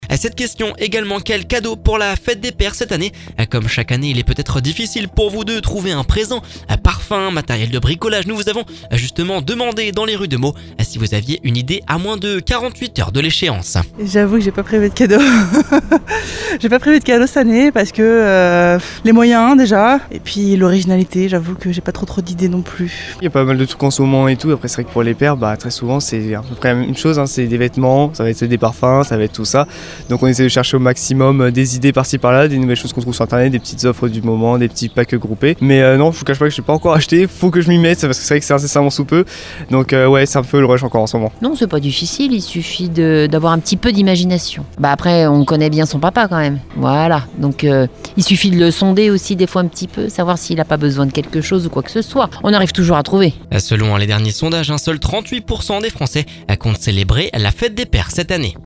Nous vous avons demandé dans les rues de Meaux si vous aviez une idée à moins de 48h de l’échéance…Selon les derniers sondages, seuls 38% des français comptent célebrer la fête des Pères !